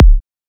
edm-kick-48.wav